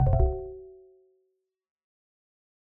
DeltaVR/DeltaVRFMOD/Assets/New_UI/User Interface, Alert, Failure, Access Denied 03 SND57522 S00.wav at 4ee6dbb92d08d87bd911c1159ab4c33ecf0ed842